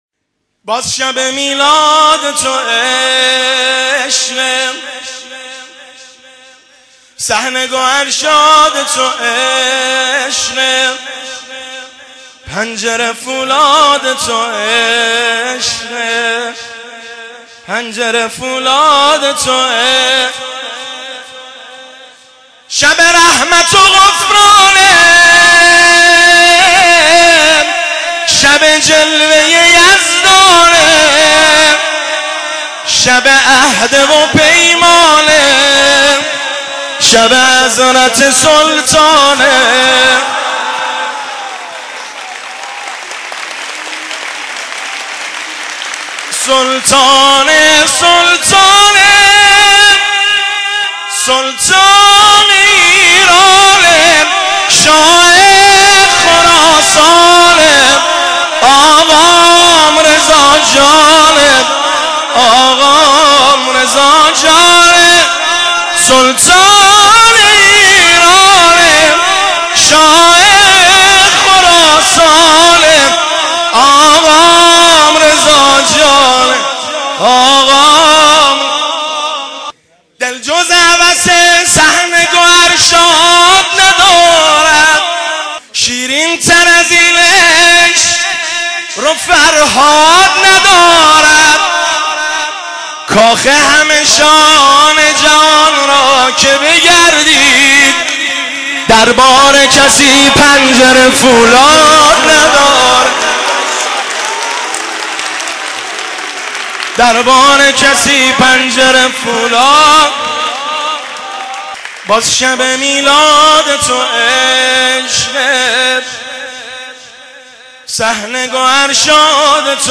مولودی خوانی